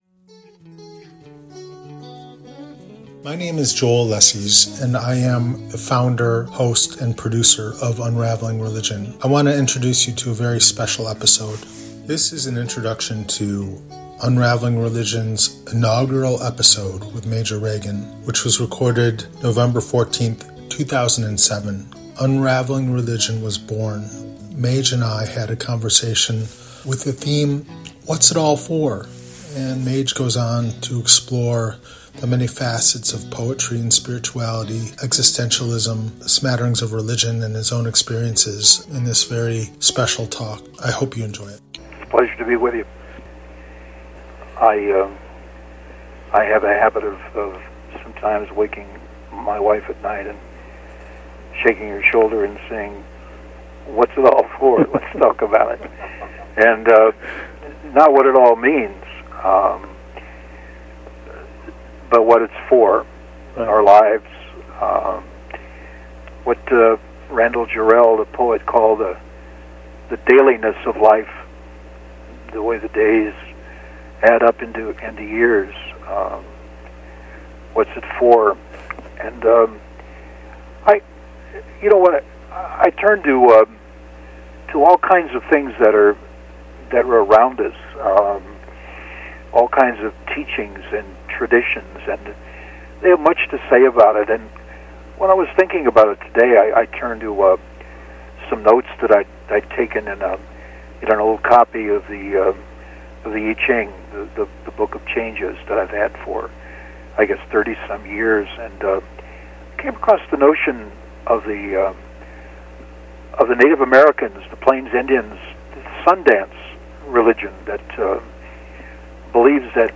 This initial epsiode of Unraveling Religion recorded on November 14th, 2007 in Buffalo, New York explores the question 'what's it all for?' in the largest scale we can imagine.